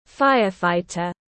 Firefighter /ˈfaɪərfaɪtər/